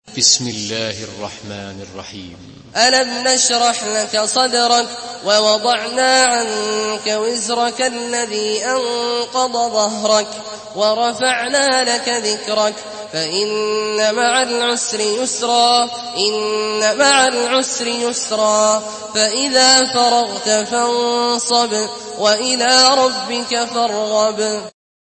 Surah Ash-Sharh MP3 by Abdullah Al-Juhani in Hafs An Asim narration.
Murattal Hafs An Asim